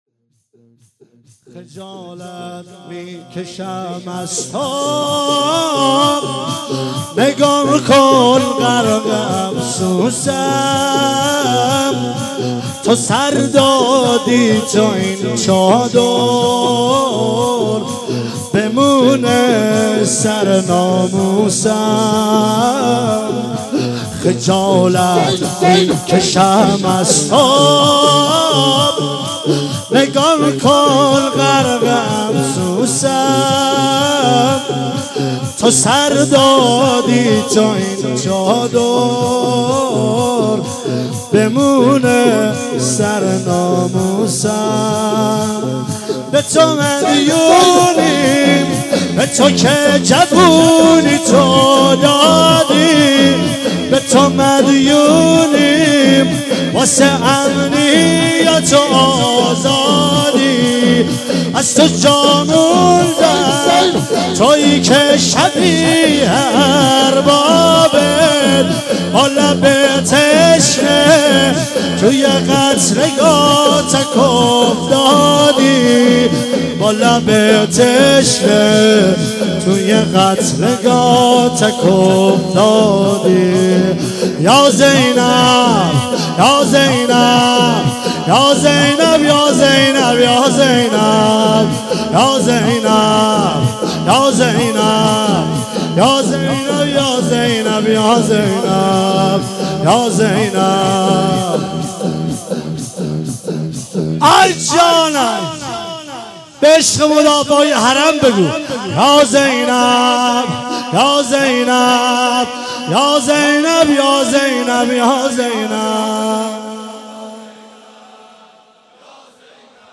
فاطمیه 96 - 10 بهمن - دامغان - شور - خجالت میکشم از تو